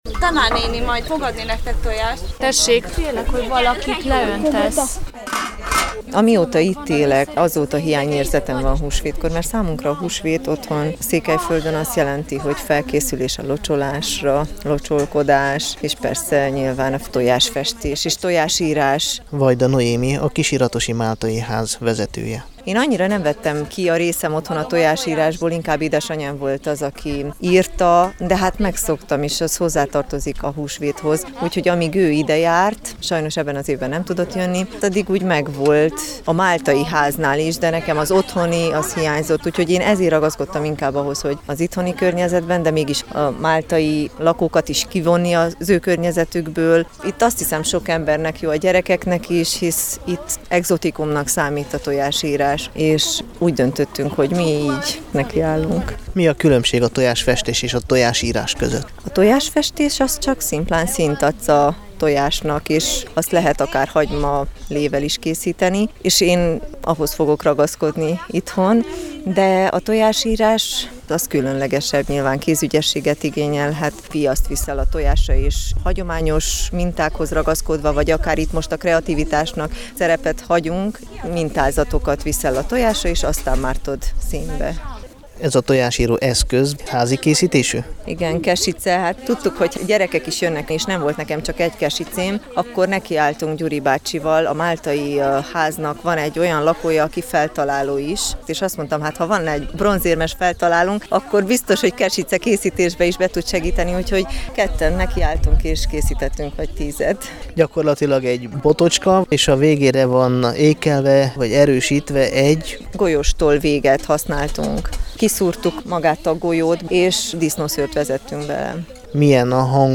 A Kisiratosi Tájház udvarán zajlott a foglalkozás.
A riport a Temesvári Rádió magyar adásában és a Kossuth Rádió Határok nélkül című műsorában hangzott el 2012 húsvétján.